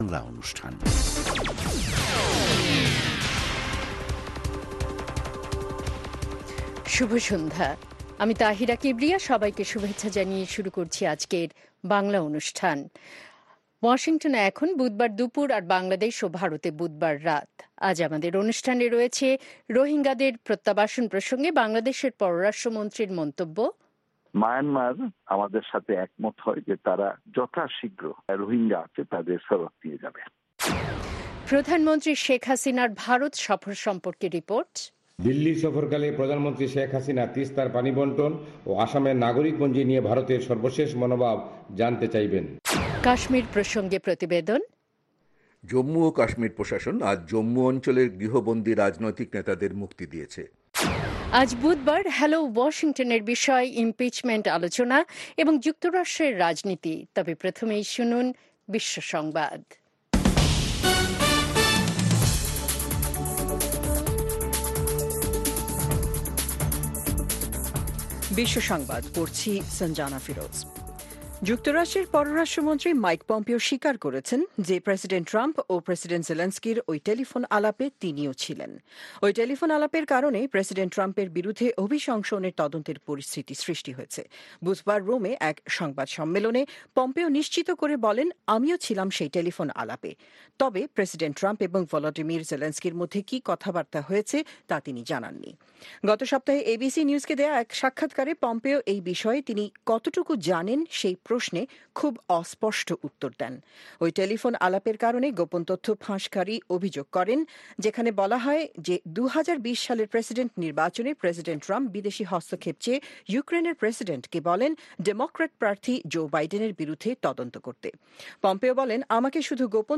অনুষ্ঠানের শুরুতেই রয়েছে আন্তর্জাতিক খবরসহ আমাদের ঢাকা এবং কলকাতা সংবাদদাতাদের রিপোর্ট সম্বলিত বিশ্ব সংবাদ, বুধবারের বিশেষ আয়োজন হ্যালো ওয়াশিংটন। আর আমাদের অনুষ্ঠানের শেষ পর্বে রয়েছে যথারীতি সংক্ষিপ্ত সংস্করণে বিশ্ব সংবাদ।